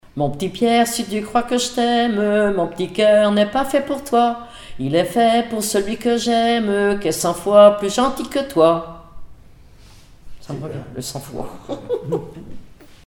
Enfantines - rondes et jeux
formulette enfantine : amusette
Pièce musicale inédite